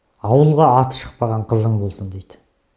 557_Hours_Kazakh_Spontaneous_Speech_Data